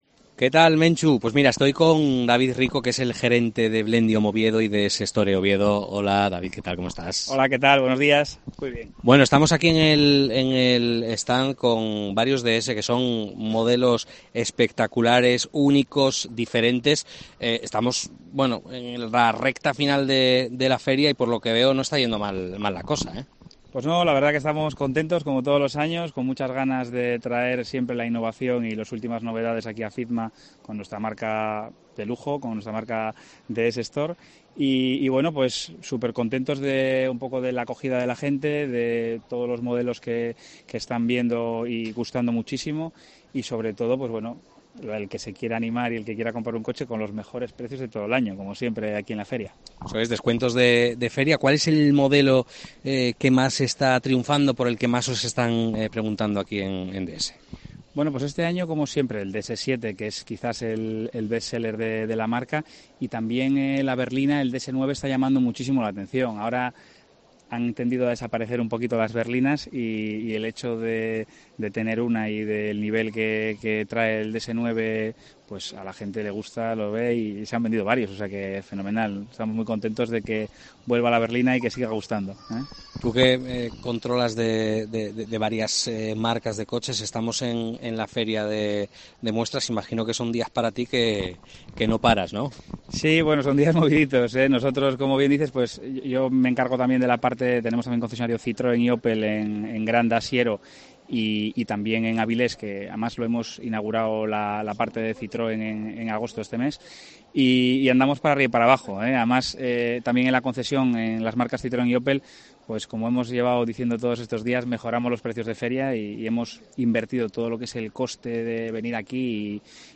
FIDMA 2023: entrevista